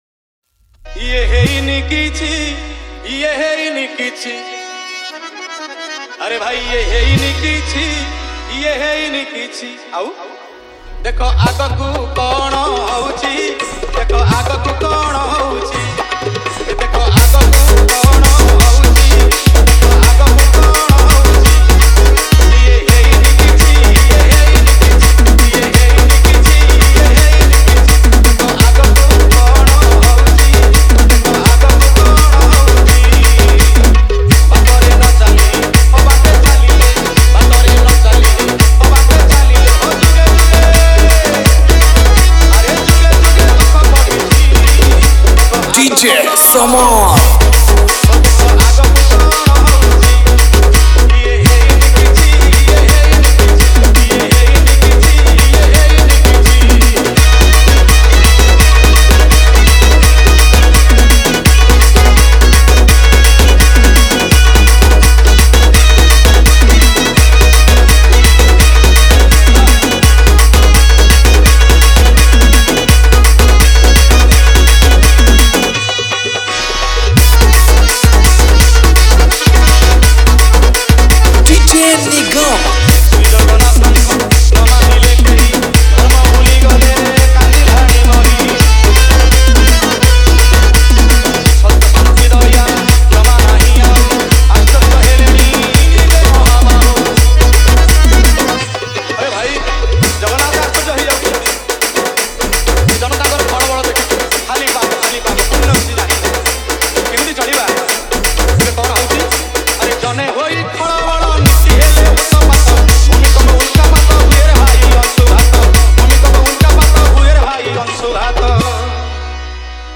Bhajan Dj Song